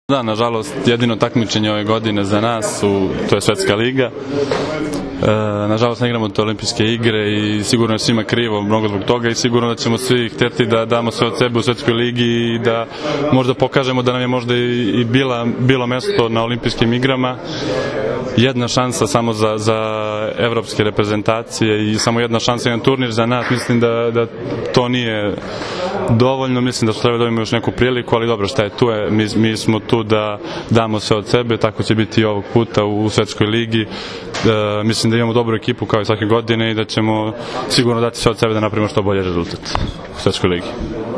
IZJAVA SREĆKA LISINCA